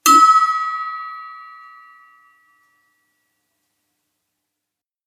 flask ping
aluminium clang ding flask hit metal metallic move sound effect free sound royalty free Sound Effects